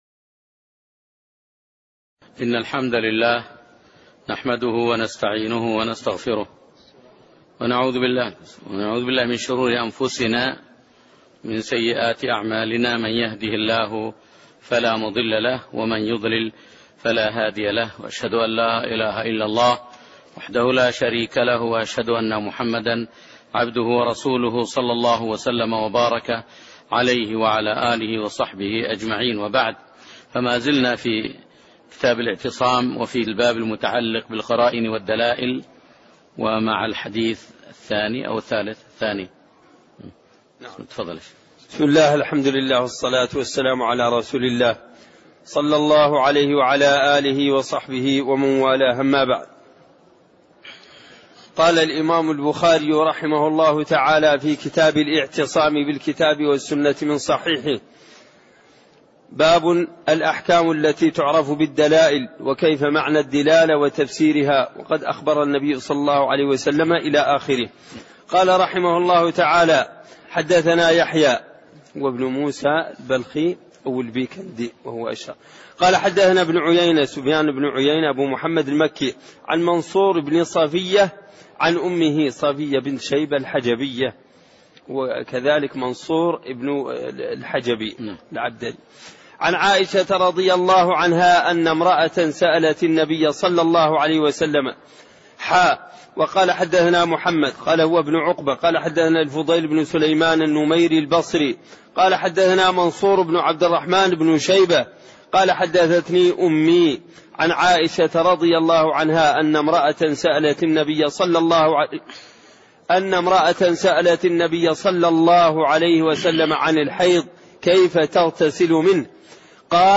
تاريخ النشر ١١ صفر ١٤٣٢ هـ المكان: المسجد النبوي الشيخ